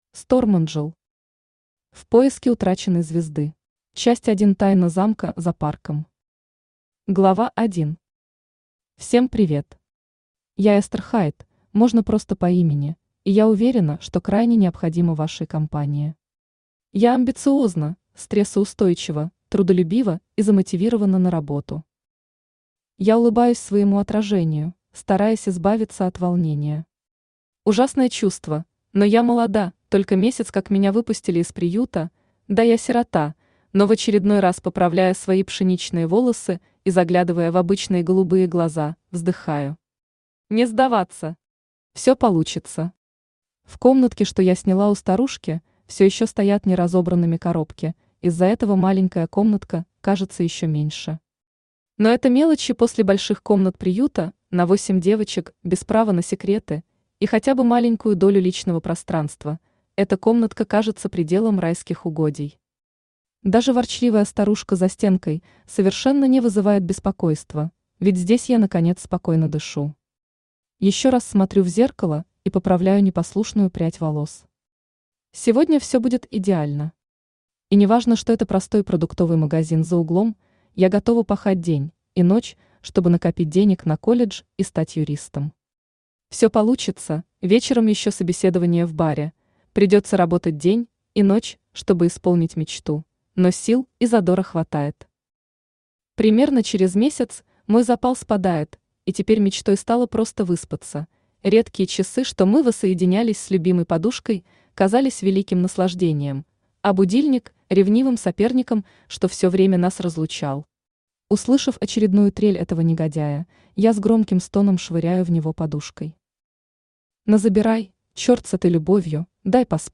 Аудиокнига В поиске утраченной звезды | Библиотека аудиокниг
Aудиокнига В поиске утраченной звезды Автор Stormangel Читает аудиокнигу Авточтец ЛитРес.